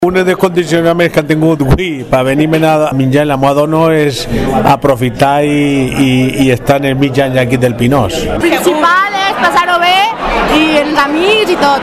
donde el público ha podido disfrutar de una tarde de música y baile